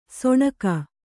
♪ soṇaka